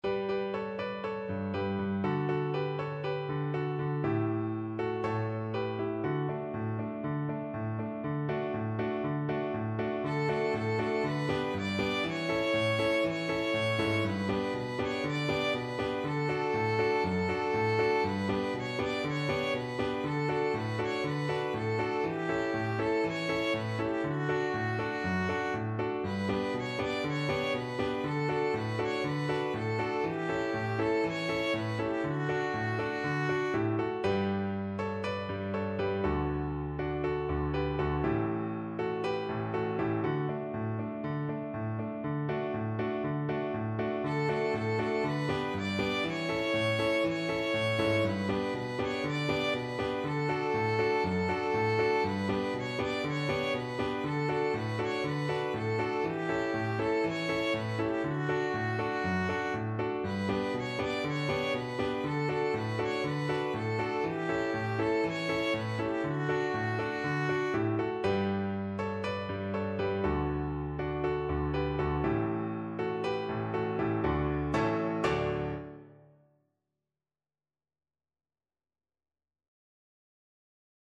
Classical Trad. Falile se Kastelanke Violin version
2/4 (View more 2/4 Music)
Allegro (View more music marked Allegro)
D major (Sounding Pitch) (View more D major Music for Violin )
Classical (View more Classical Violin Music)
Croatian